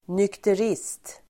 Ladda ner uttalet
Folkets service: nykterist nykterist substantiv, teetotaller Uttal: [nykter'is:t] Böjningar: nykteristen, nykterister Definition: person som av princip inte dricker alkohol; absolutist (total abstainer)